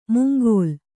♪ mungōl